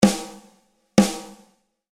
Detune - gezielte Verstimmung
Ausgehend von einer guten, gleichmäßigen Stimmung kann man durch Verstimmung bestimmte Klangvarianten erzielen.
Durch diese partielle Verstimmung des Schlagfells wird die Snaredrum nun einen satten und kurzen Sound mit wenig Oberton erzeugen.